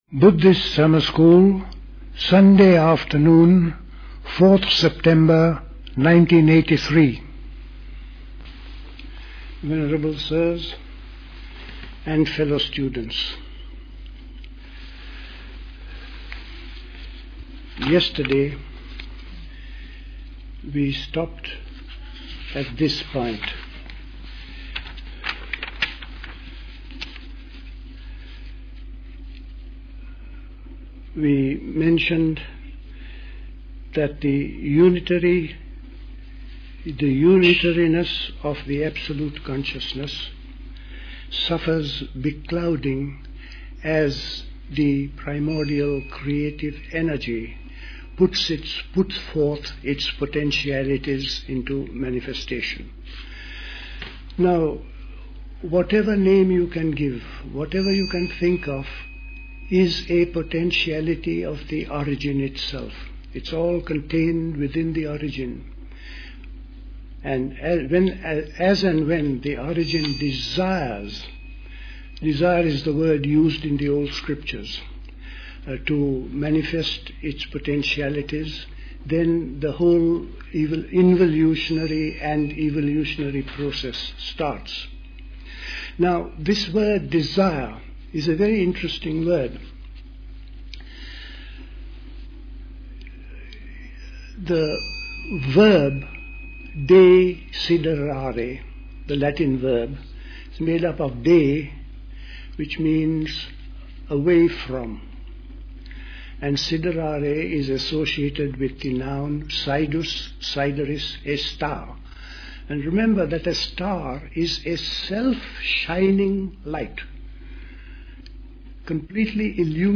The Buddhist Society Summer School Talks